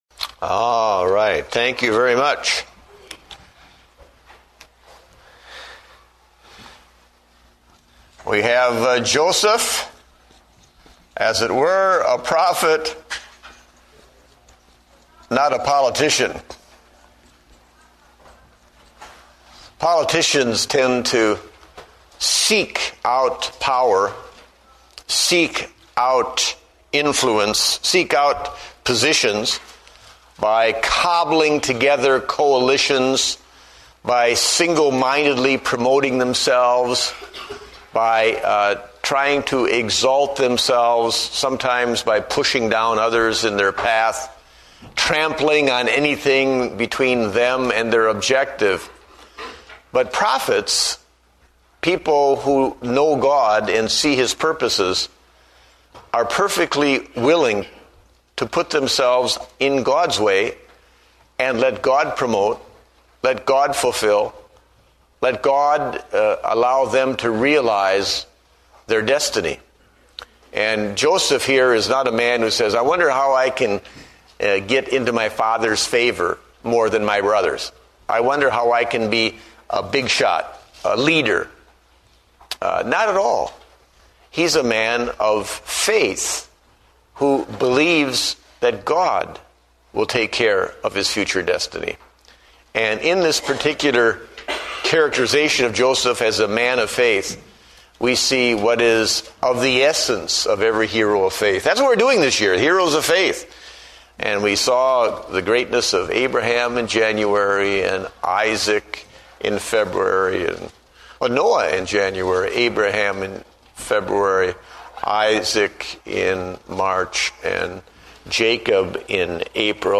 Date: May 10, 2009 (Adult Sunday School)